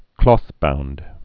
(klôthbound, klŏth-)